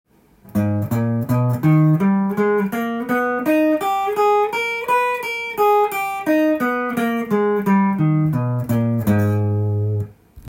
Ａ♭ｍＭ７（９）